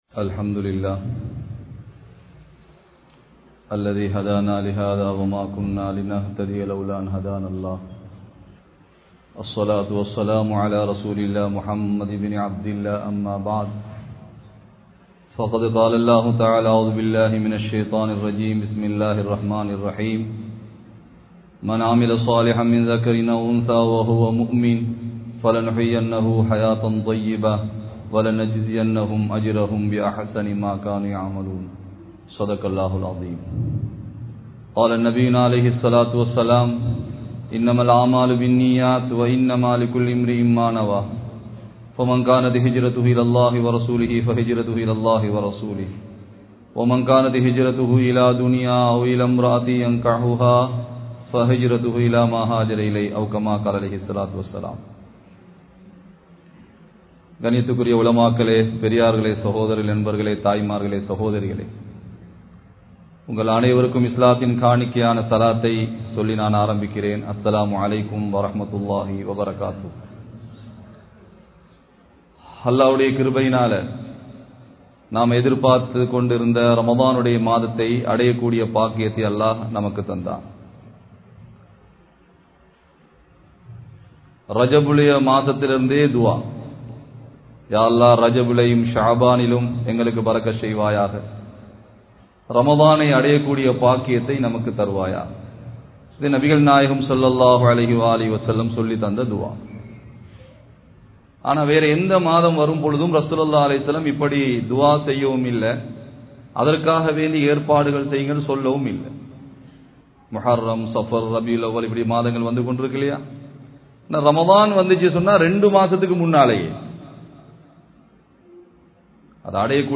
Nabi(SAW)Avarhaludan Suvarkaththil Irukka Veanduma? (நபி(ஸல்)அவர்களுடன் சுவர்க்கத்தில் இருக்க வேண்டுமா?) | Audio Bayans | All Ceylon Muslim Youth Community | Addalaichenai